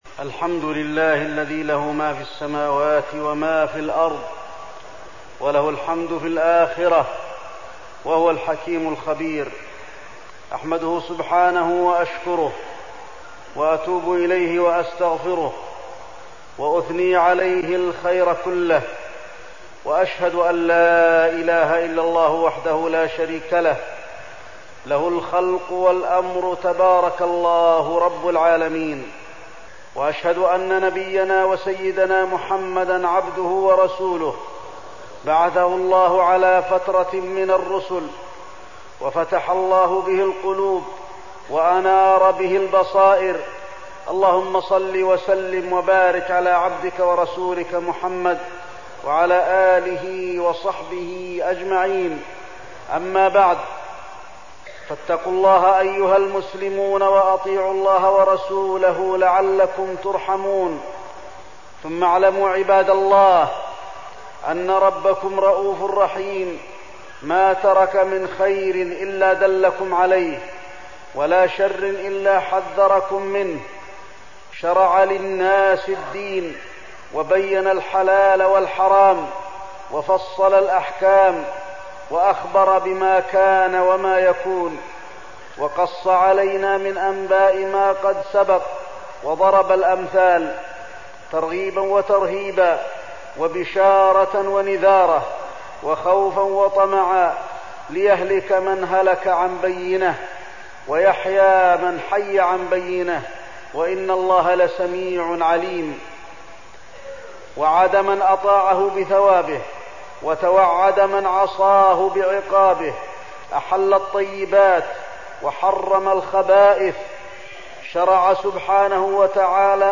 تاريخ النشر ٩ ربيع الثاني ١٤١٧ هـ المكان: المسجد النبوي الشيخ: فضيلة الشيخ د. علي بن عبدالرحمن الحذيفي فضيلة الشيخ د. علي بن عبدالرحمن الحذيفي المخدرات